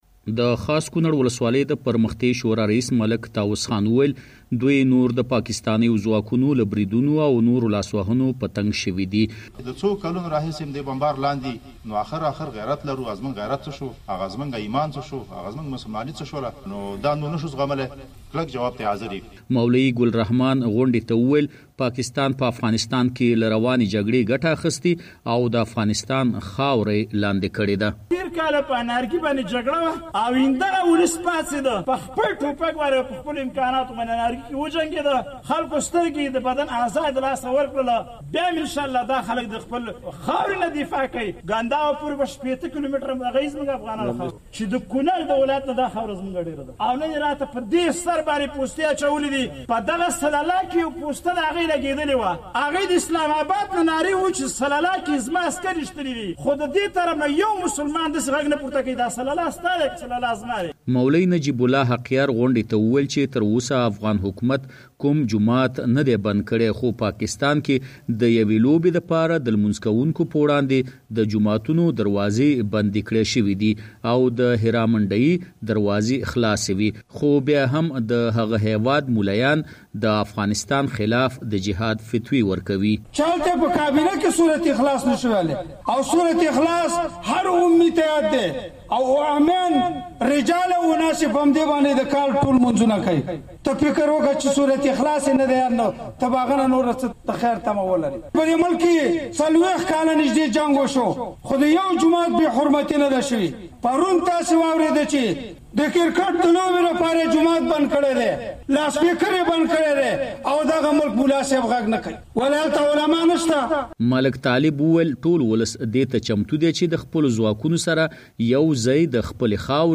قومي مشرانو دا خبرې خاص کونړ کې جوړې شوې غونډه کې وکړې.